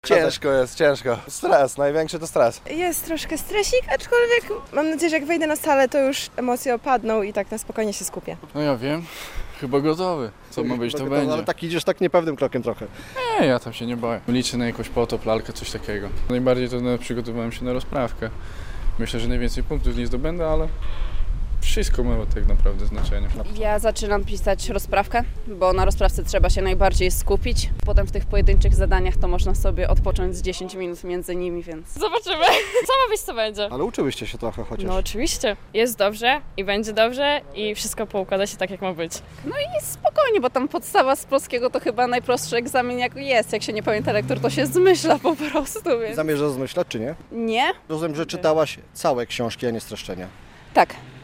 Pierwszy dzień matur - uczniowie zmierzą się z językiem polskim - relacja